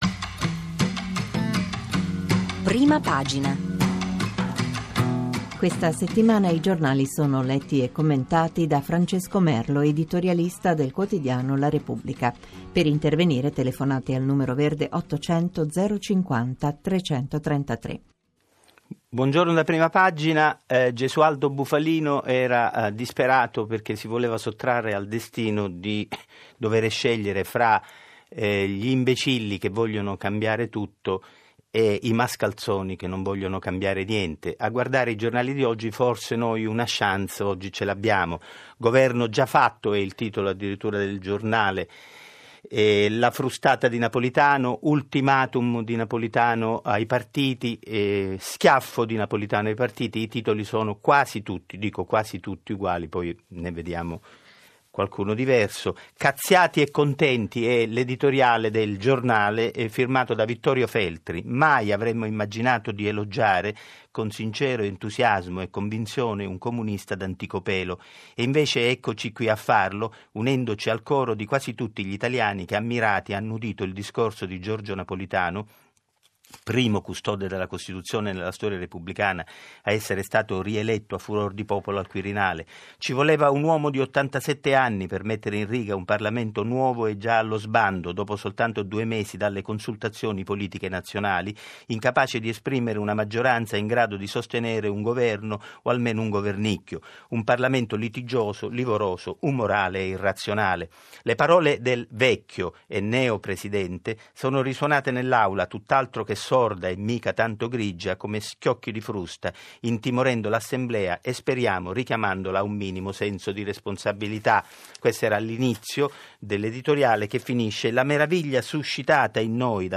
Il discorso di Giorgio Napolitano per il suo secondo settennato da Presidente della Repubblica, 22 aprile 2013 | POLITICA DEI SERVIZI SOCIALI